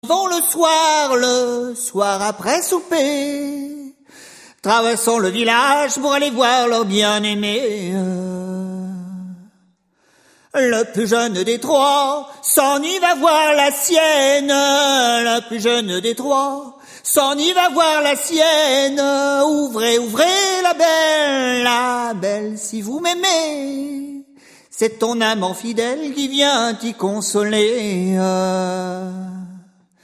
Musique : Traditionnel
Interprètes : Les Routiniers
Origine : Bretagne
Chant par Les Routiniers sur l'album En avant deux ! en 2008 (extrait Nozbreizh)